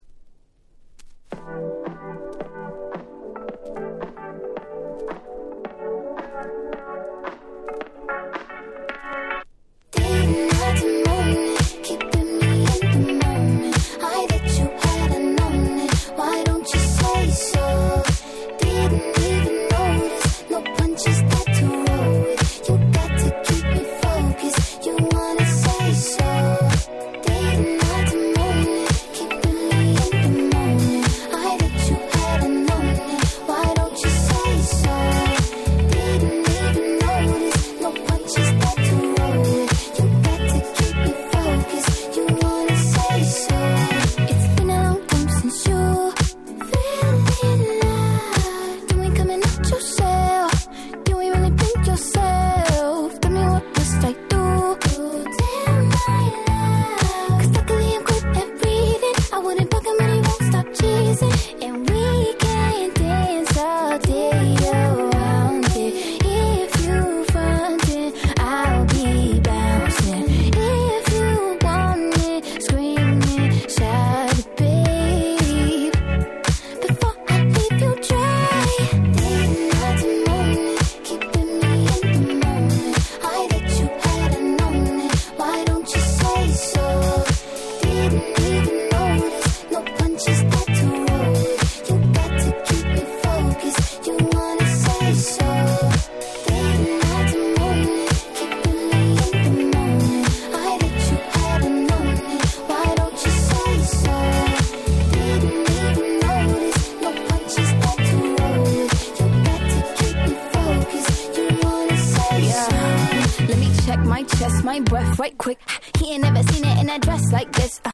20' Super Hit R&B !!